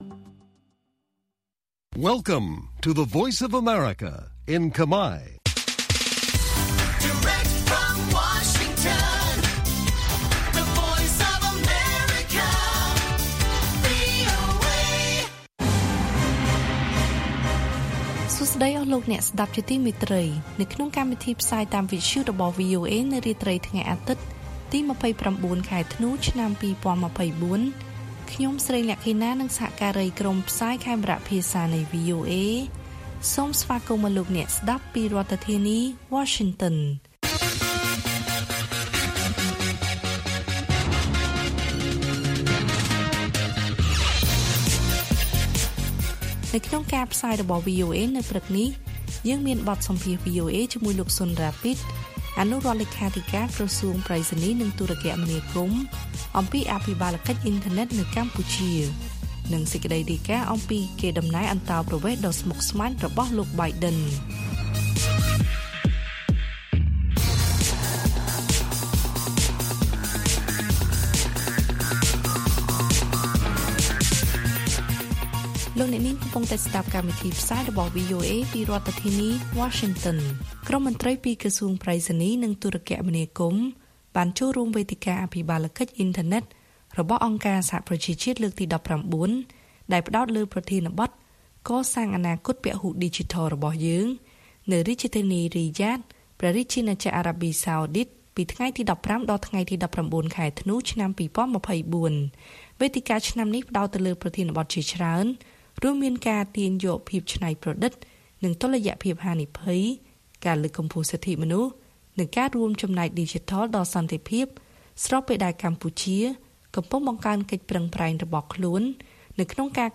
ព័ត៌មាននៅថ្ងៃនេះមានដូចជា បទសម្ភាសន៍ VOA ជាមួយលោក ស៊ុន រ៉ាពីដ អនុរដ្ឋលេខាធិការក្រសួងប្រៃសណីយ៍ និងទូរគមនាគមន៍ អំពីអភិបាលកិច្ចអ៊ីនធឺណិតនៅកម្ពុជា។ កេរដំណែលអន្តោប្រវេសន៍របស់លោក Biden ជាការស្មុគស្មាញ។